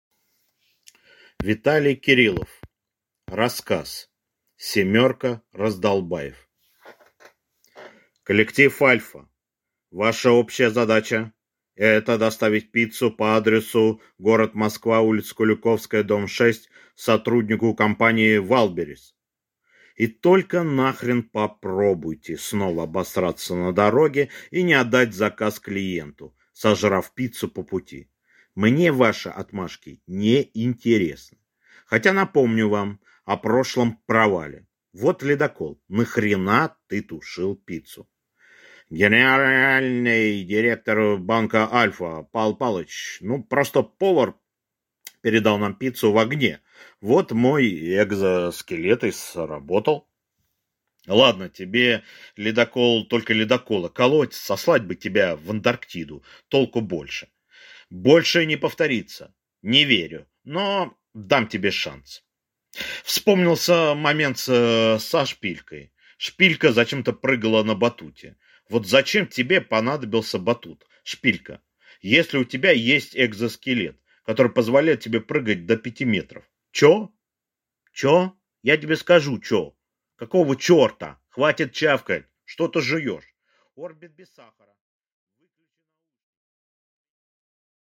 Аудиокнига Семёрка раздолбаев | Библиотека аудиокниг